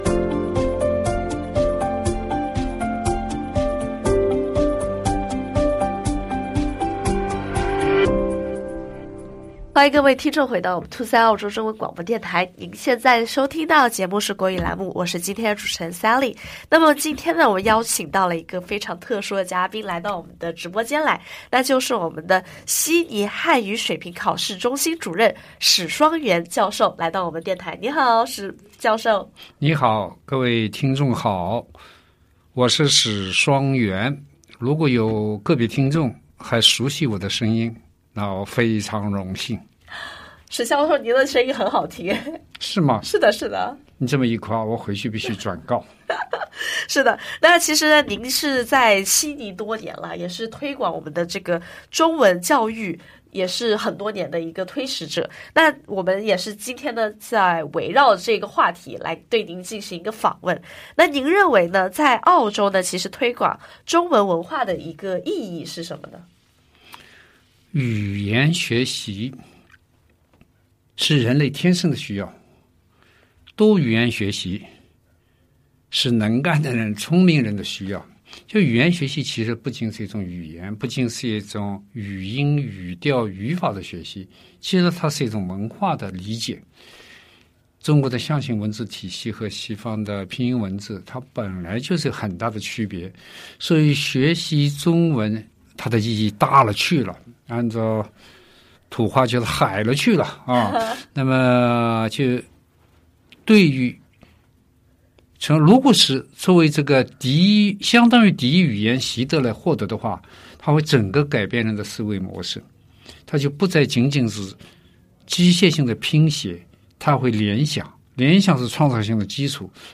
访问录音：